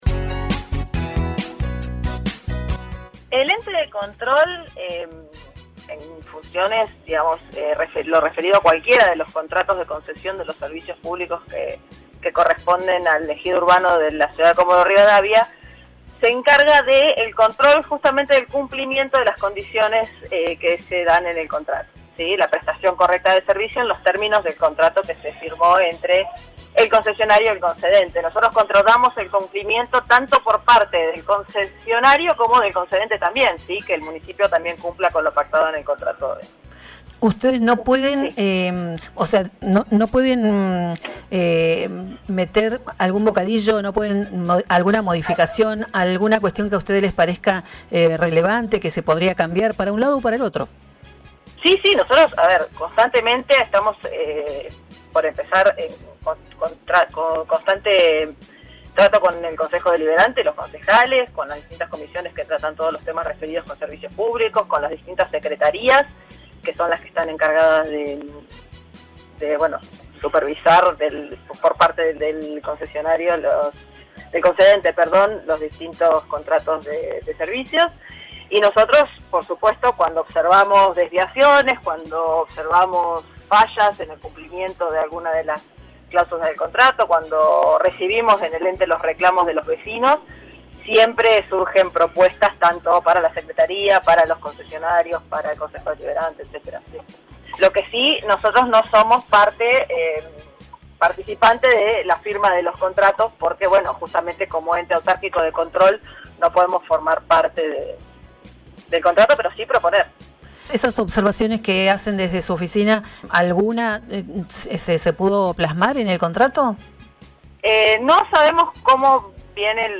Lucía Haag es integrante del Ente de Control de Servicios Públicos y en diálogo con Radiovision, manifestó la posición que les toca asumir en todo lo relacionado a las vinculaciones de los prestadores de servicios al Municipio de Comodoro Rivadavia.